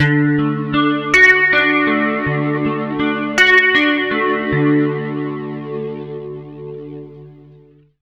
80MAJARP D-L.wav